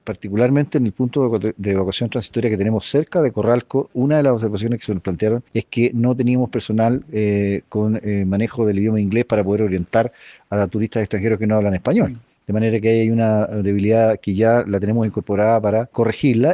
Hugo Vidal, alcalde de Curacautín, además de agradecer a todos los que intervinieron en el desarrollo del ejercicio, sin dejar de lado lo prioritario que es reforzar las comunicaciones en la zona, precisó que la jornada arrojó mucho aprendizaje.